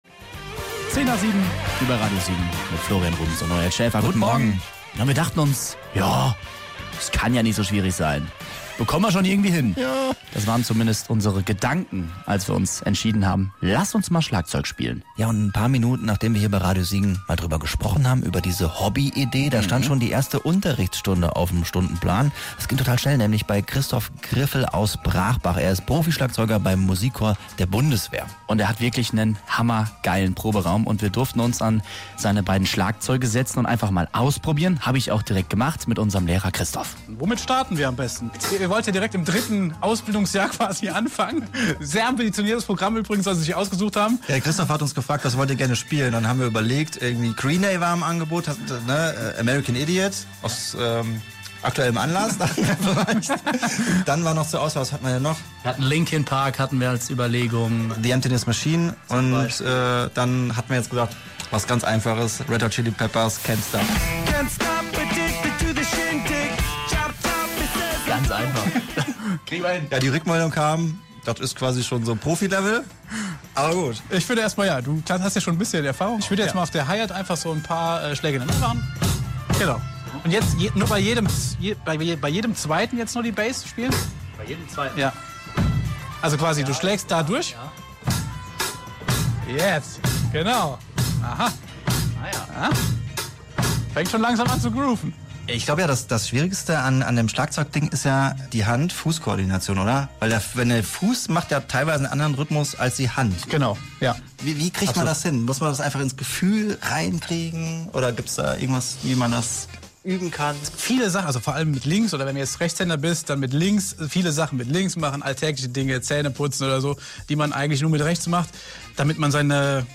Neues Hobby: Schlagzeug für Anfänger
In der ersten Stunde stand das Schlagzeugspielen auf dem Stundenplan.